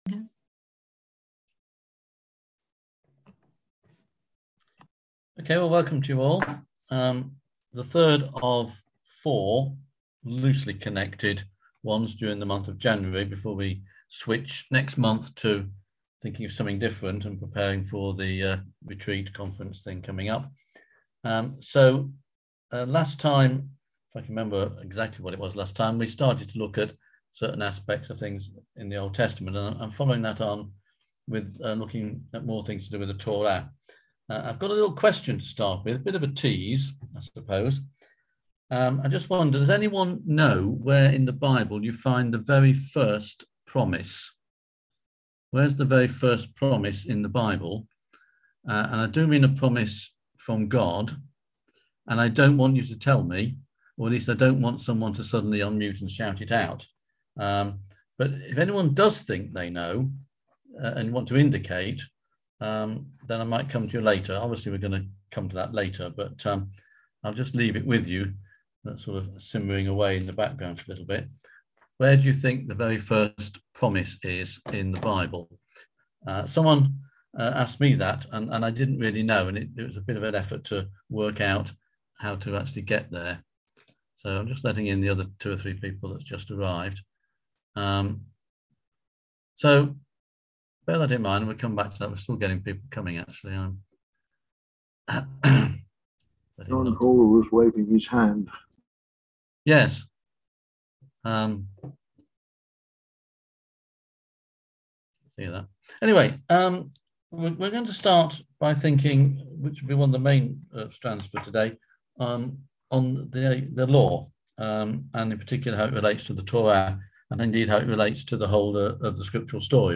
On January 20th at 7pm – 8:30pm on ZOOM